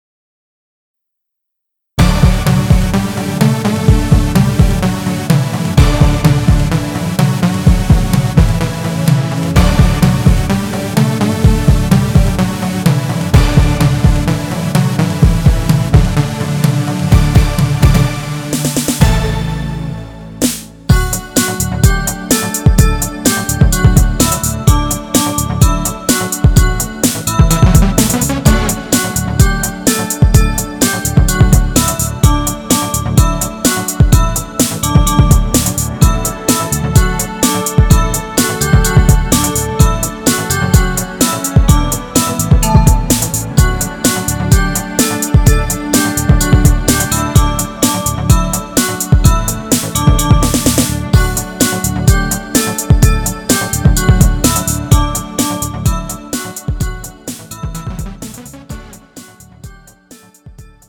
음정 D 키
장르 가요 구분 Pro MR